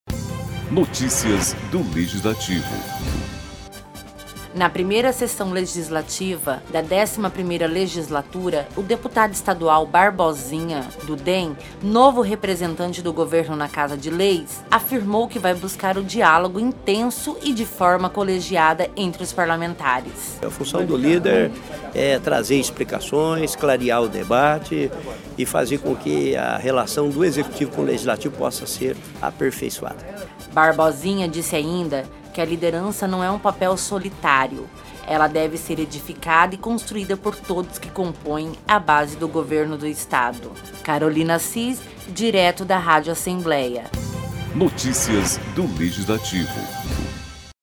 Durante a 1ª sessão realizada nesta segunda-feira (4), na Casa de Leis, o líder Barbosinha afirmou que Mato Grosso do Sul está pronto para avançar e fazer parter da honrosa tarefa de contribuir para o Brasil.